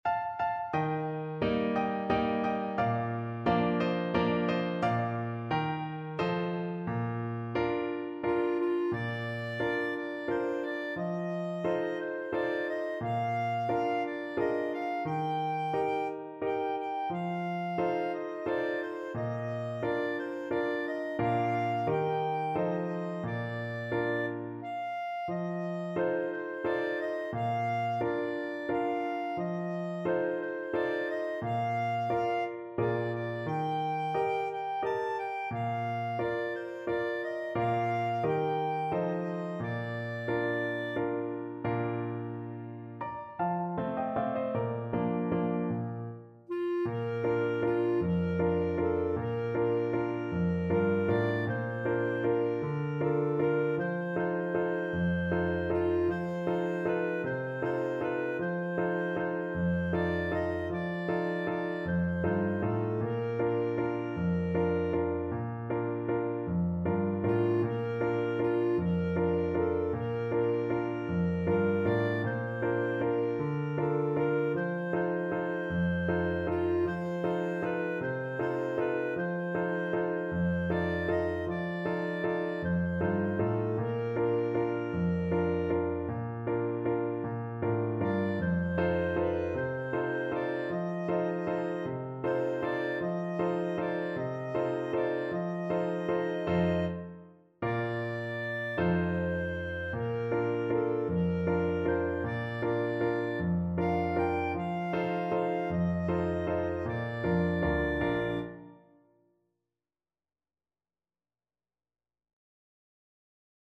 3/4 (View more 3/4 Music)
Slow =c.88
Traditional (View more Traditional Clarinet Music)